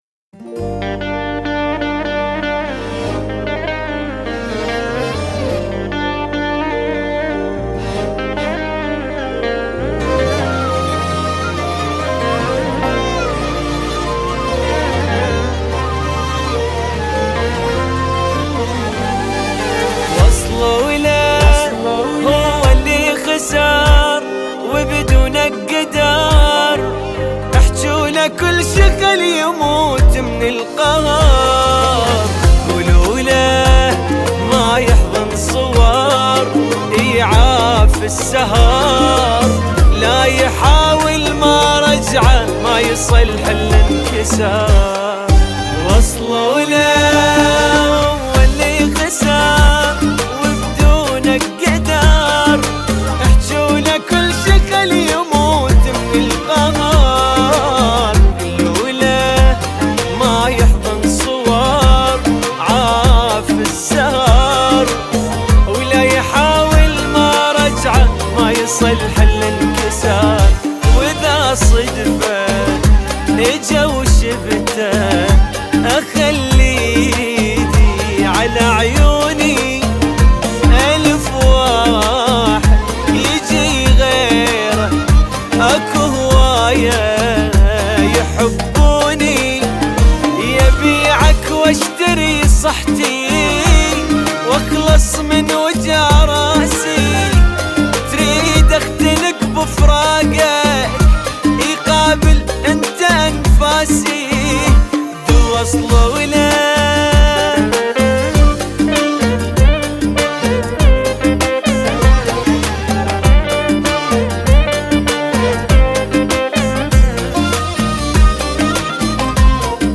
Arab Song